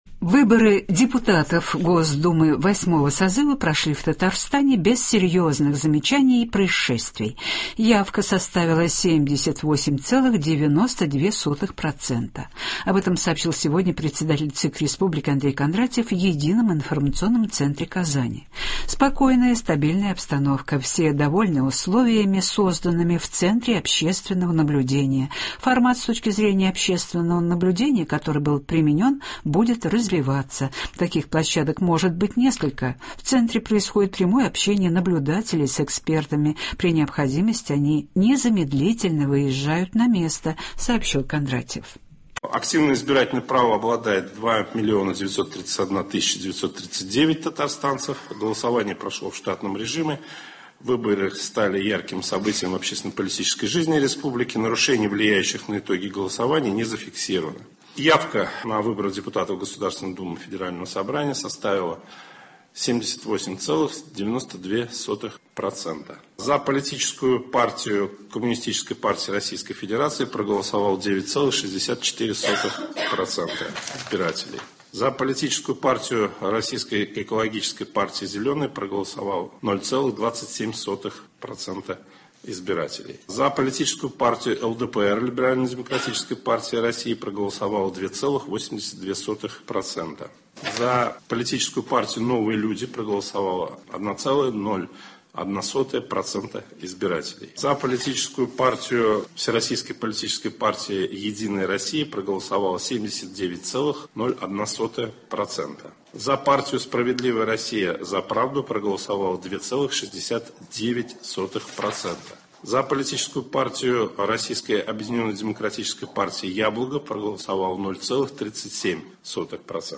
Новости (20.09.2021)